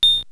ice_crack.wav